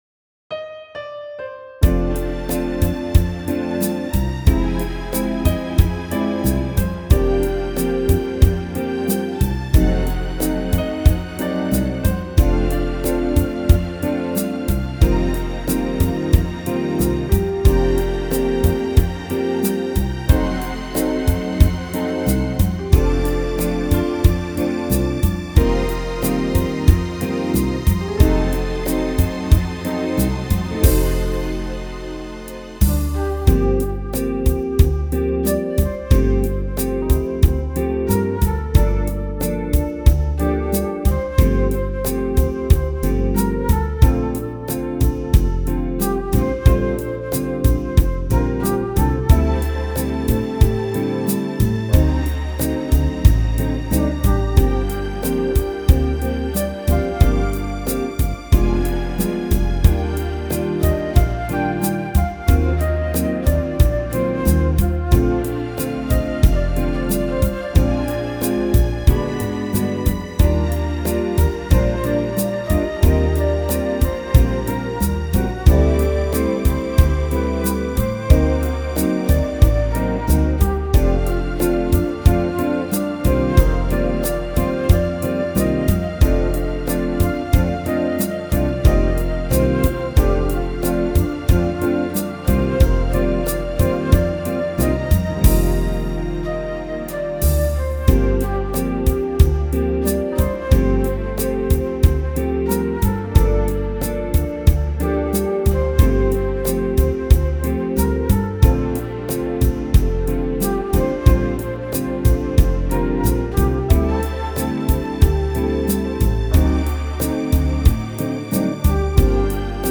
and it is smooth – smooth – smooth!